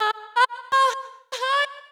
House / Voice / VOICEGRL088_HOUSE_125_A_SC2.wav